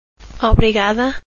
Obrigada   Obrigahda – only said by females *